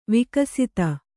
♪ vikasita